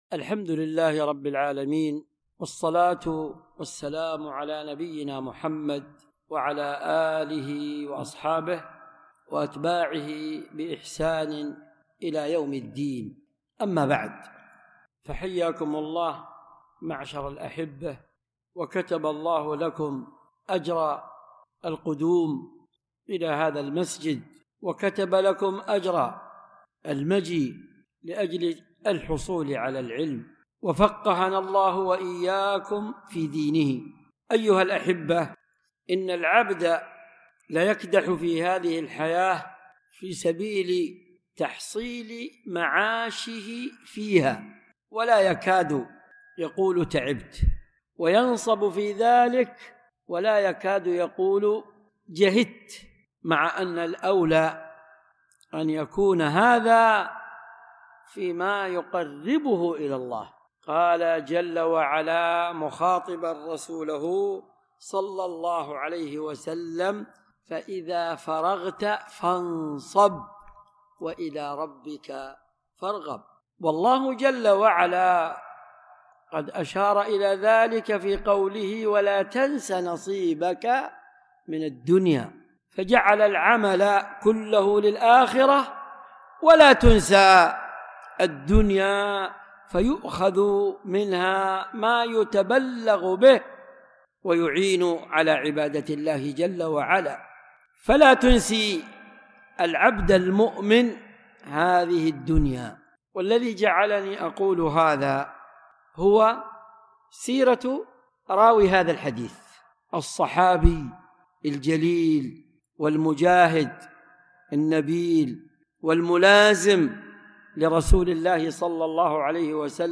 ألقيت بعد مغرب الأحد 7 صفر 1446هـ.